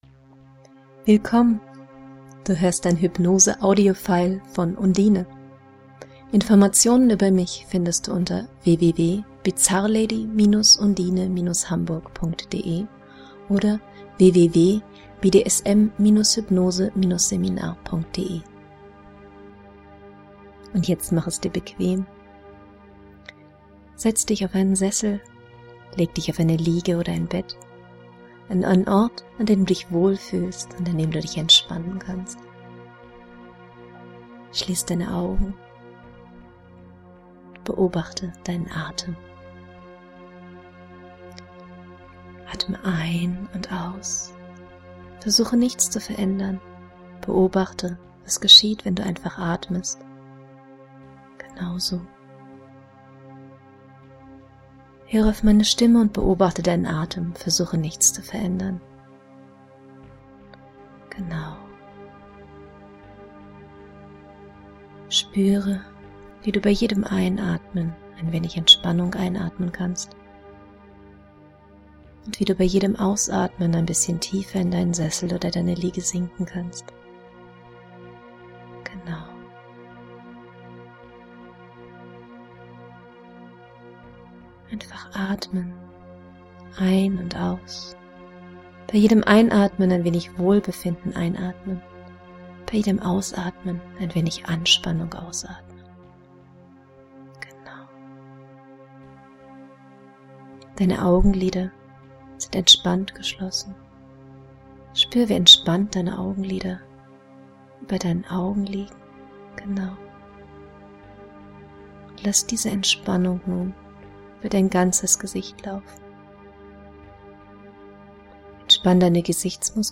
Es handelt sich um eine sogenannte „Leerhypnose“, also um eine Induktion, verschiedene Vertiefungen und Exduktion, ohne Wirksuggestion. Diese Hypnose hinterlässt nichts als ein Wohlgefühl … ideal, um den Zustand der Trance kennenzulernen.